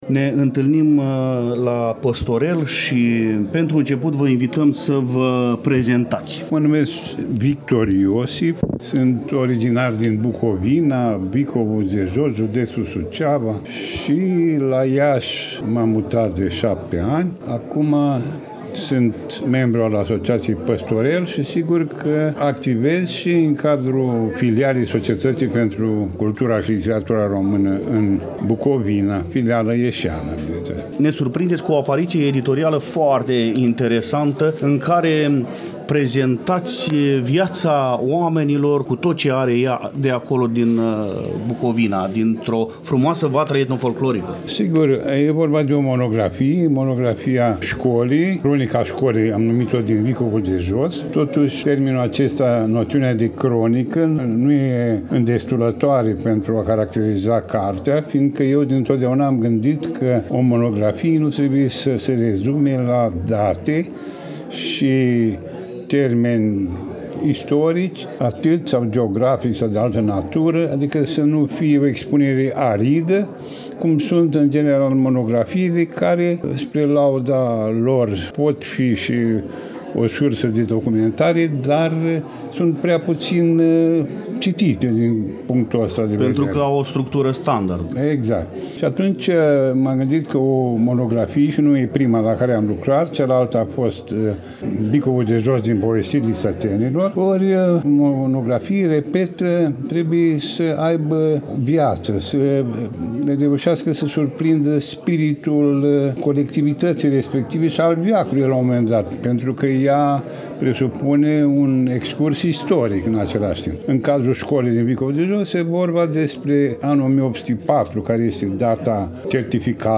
din incinta sălii de lectură a Bibliotecii Județene „Gh. Asachi” Iași